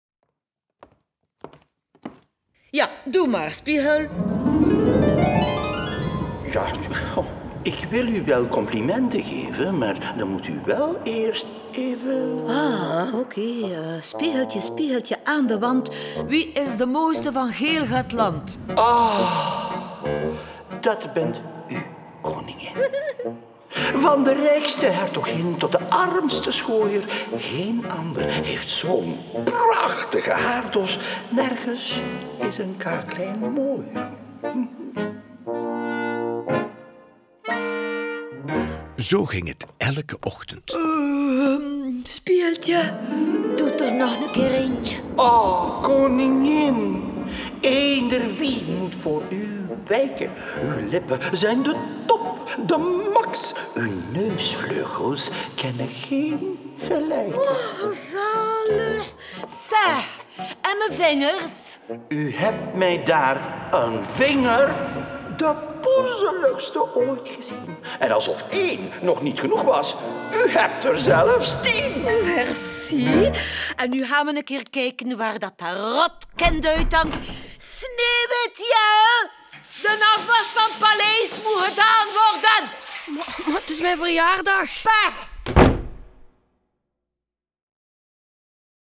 Klarinet en Basklarinet
Viool